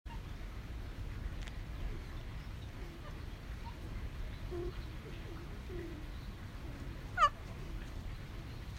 この声はワオキツネザルが草を食べているときに独り言のように発している声です。